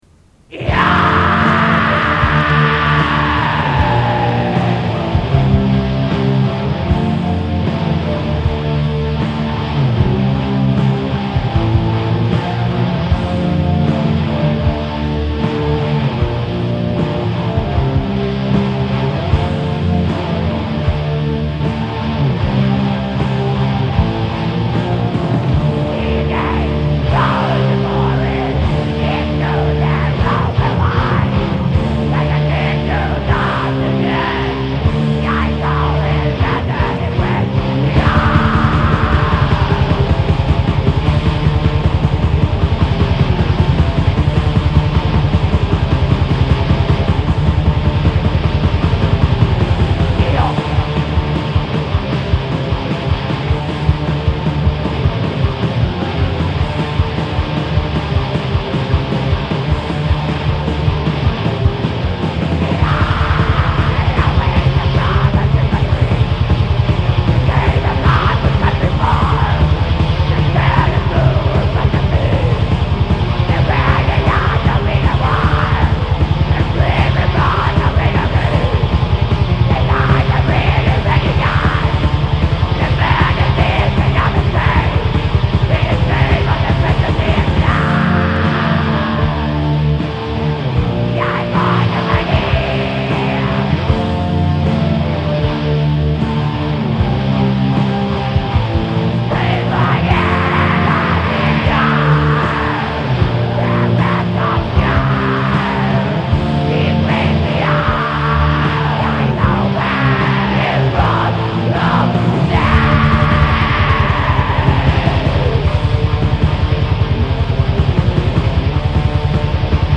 [ Metal - 04:41 / 5.3Mb ] [Info] [